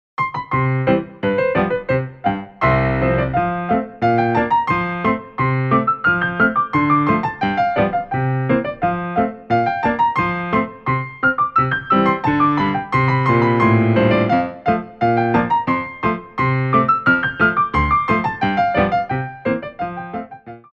Petit Allegro 2
2/4 (8x8)